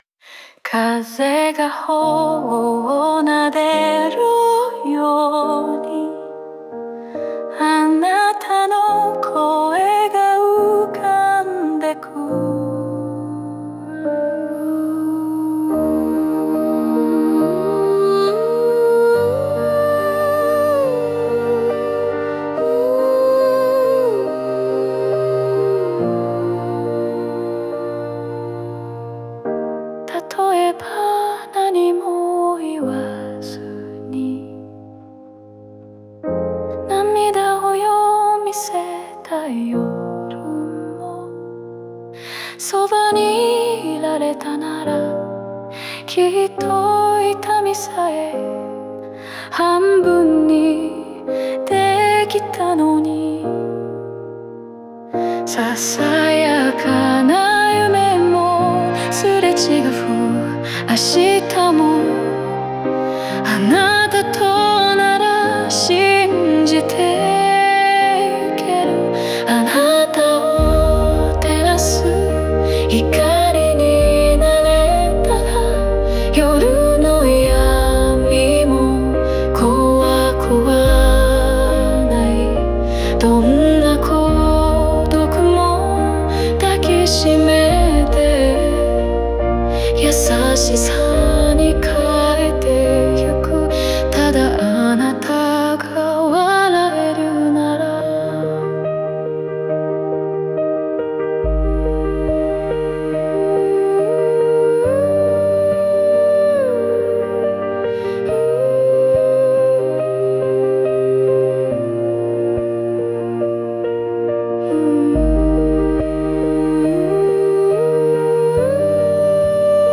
オリジナル曲♪
この楽曲は、大切な人を想い、その人の心に寄り添いたいと願う気持ちを描いたバラードです。
日常の中にある痛みや不安を、そっと包み込むようなメッセージと共に、静かに情感が高まっていきます。